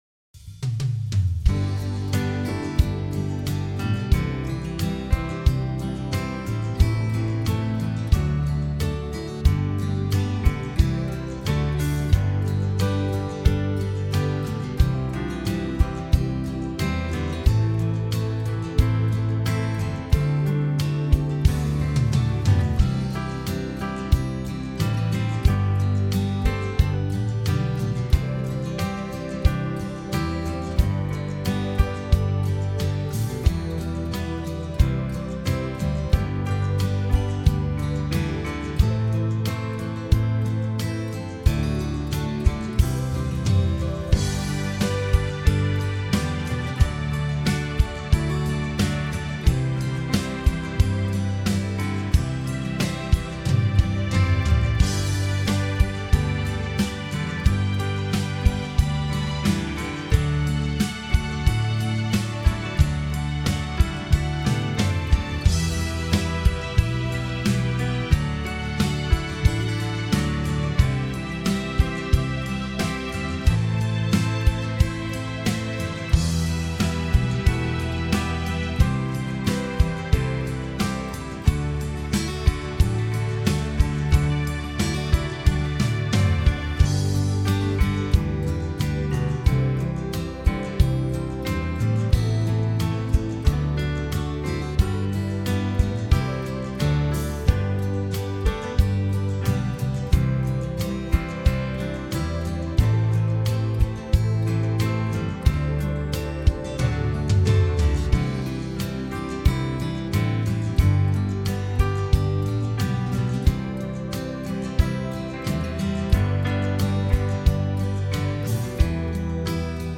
Home > Music > Rock > Bright > Smooth > Medium